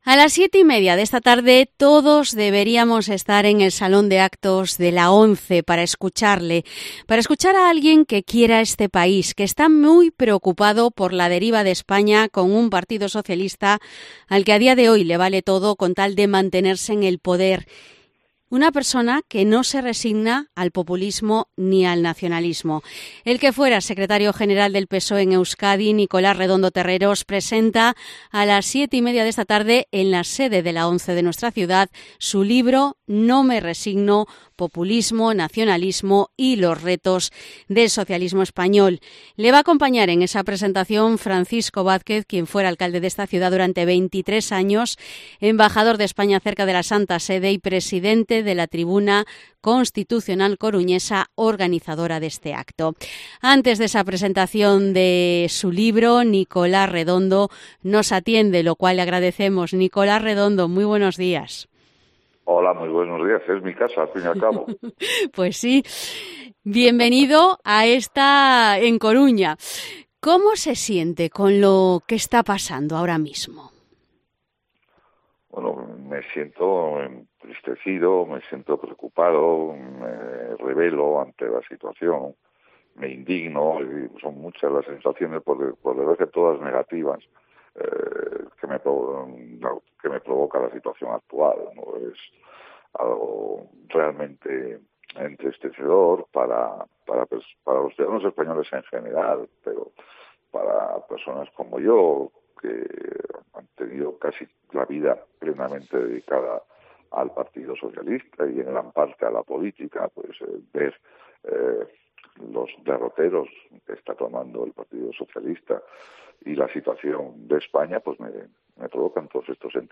Entrevista Nicolás Redondo Terreros